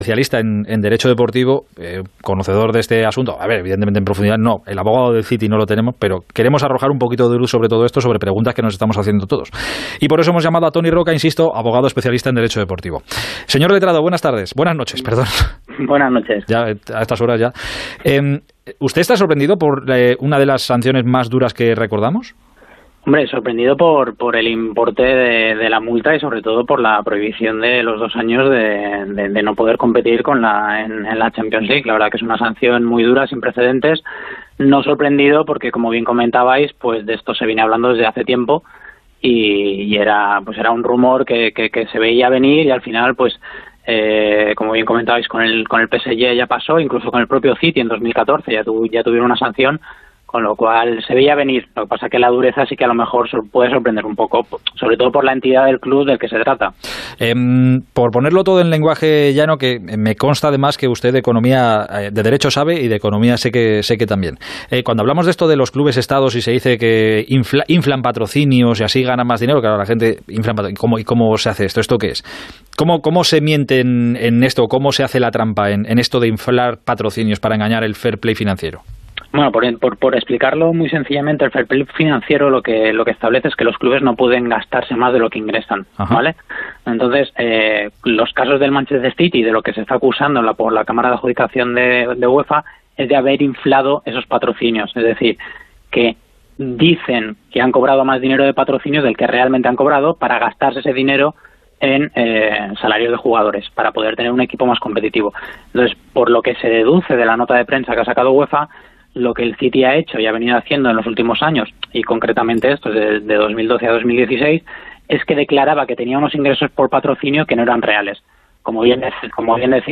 ESCUCHAR LA ENTrEVISTA COMPLETA EN ONDA CERO